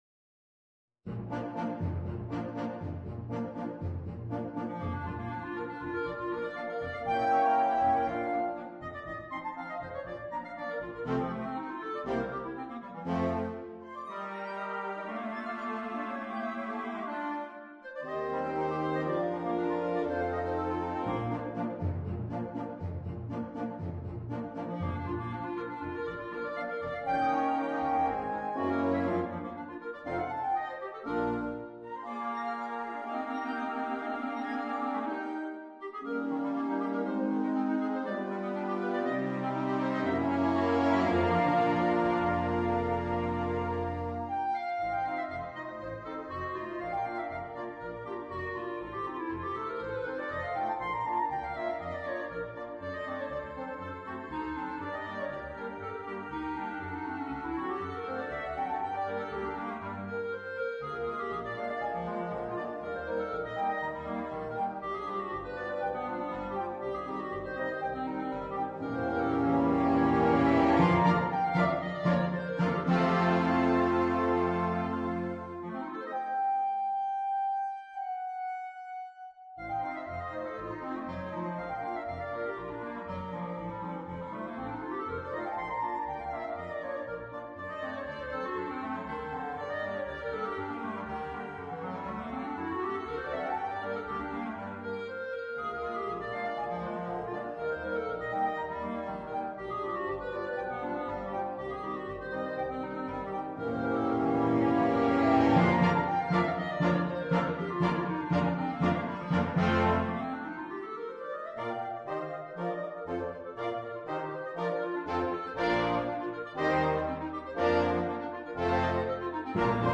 clarinetto e banda